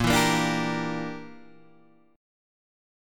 A#6b5 chord